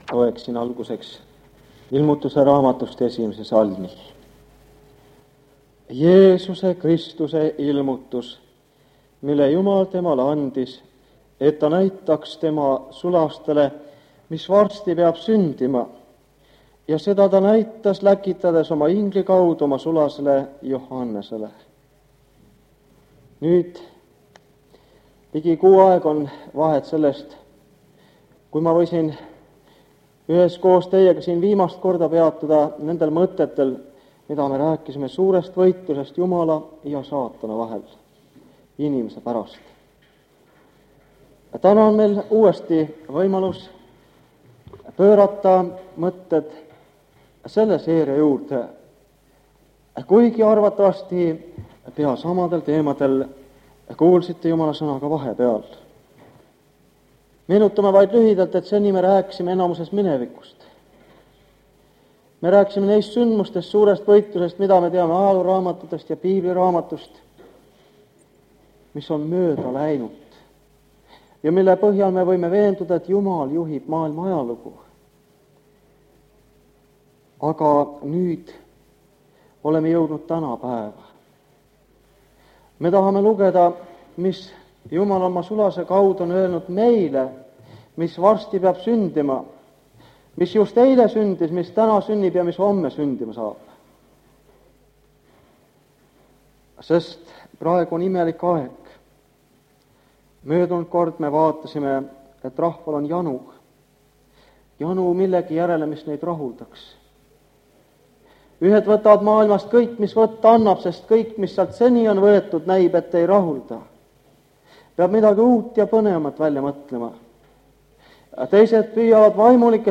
Ilmutuse raamatu seeriakoosolekud Kingissepa linna adventkoguduses
Jutlused